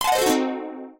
Emitter: Sparkles: Sound effect